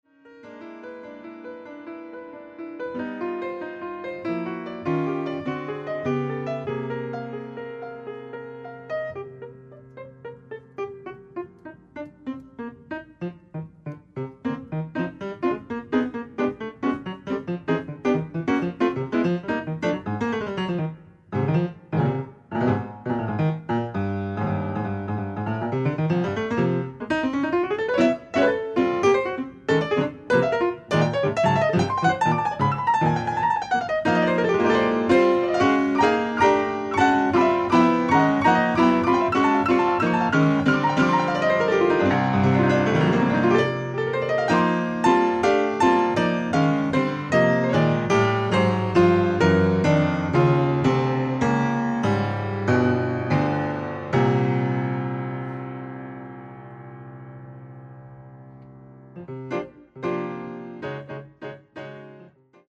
pianoforte
sassofono tenore
contrabbasso
batteria